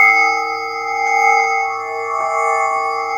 A#4 DRONEB01.wav